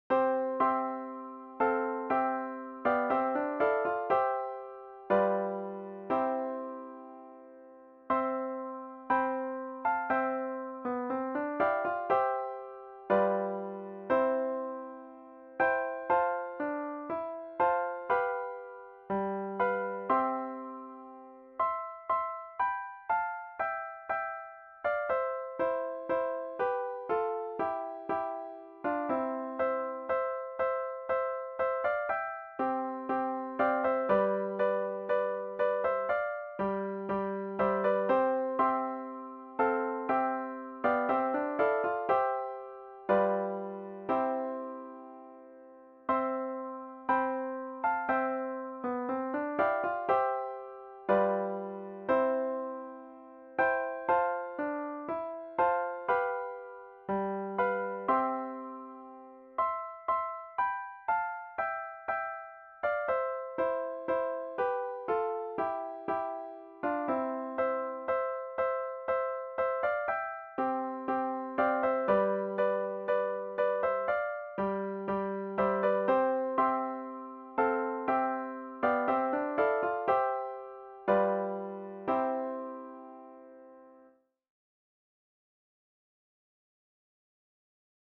Guitar 1 should be played in fifth position.